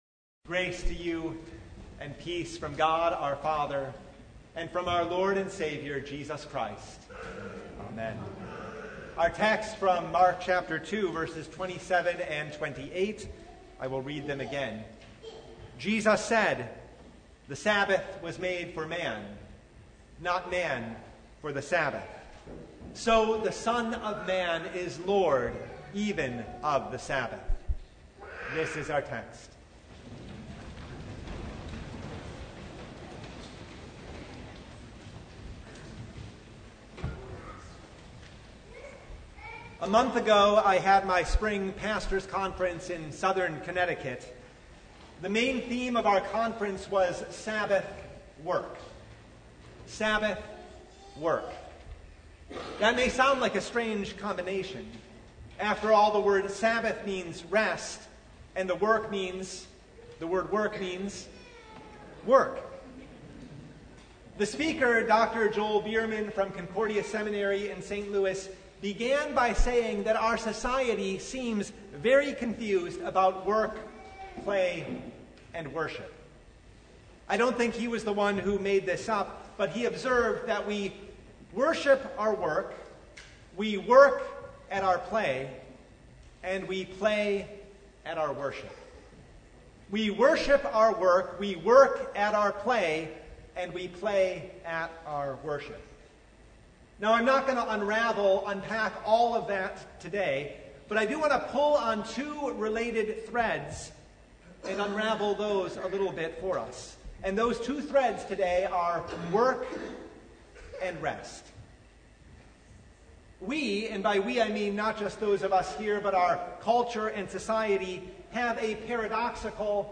Mark 2:23–3:6 Service Type: Sunday Both work and rest find their meaning in Jesus Christ